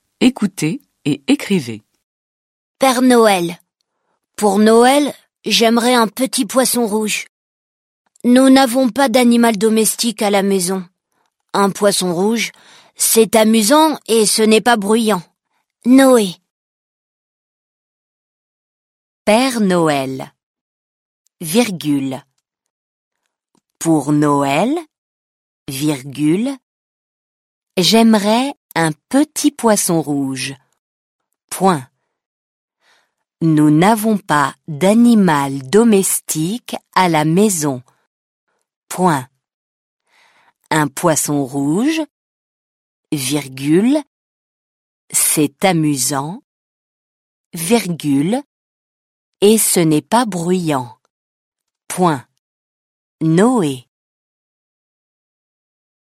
دیکته - مبتدی